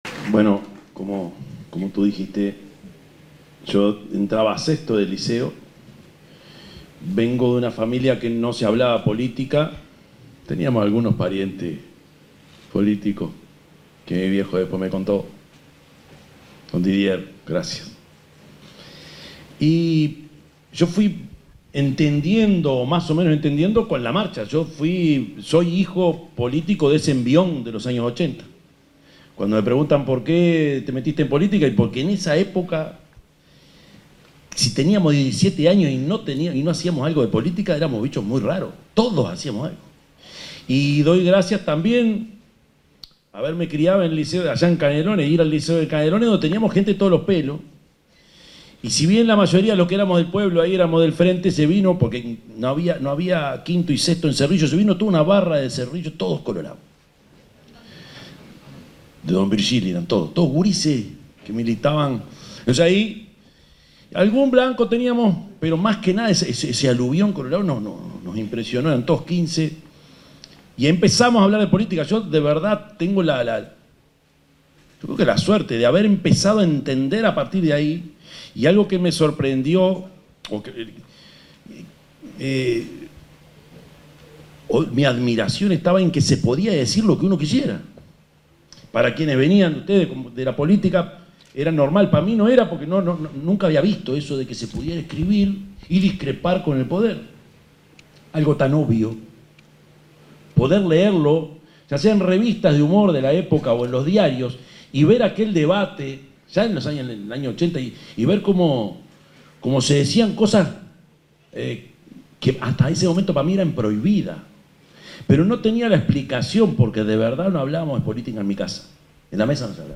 Palabras del presidente Yamandú Orsi en celebración de los 40 años de democracia
El presidente de la República, Yamandú Orsi, participó de un encuentro en celebración de los 40 años de democracia desde 1985.